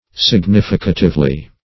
significatively - definition of significatively - synonyms, pronunciation, spelling from Free Dictionary
[1913 Webster] -- Sig*nif"i*ca*tive*ly, adv. --